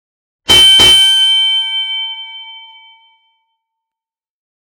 Category ⚡ Sound Effects